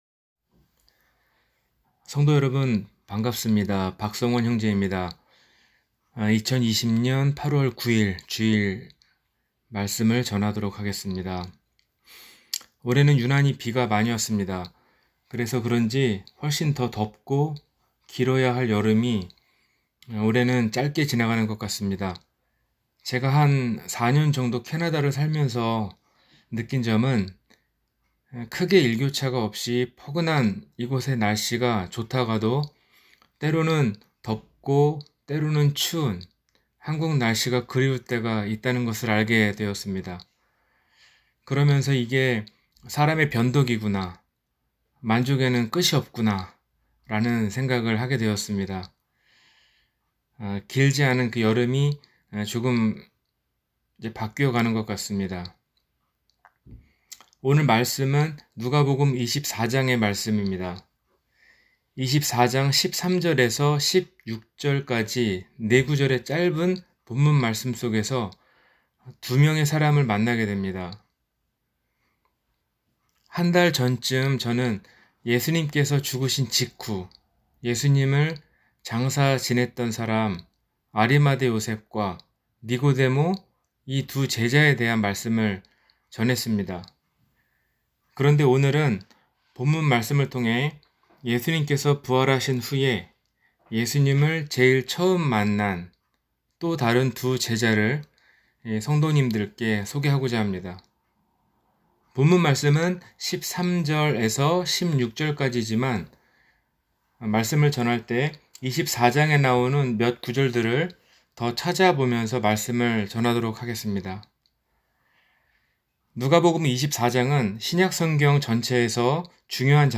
엠마오로 가는 두 제자 – 주일설교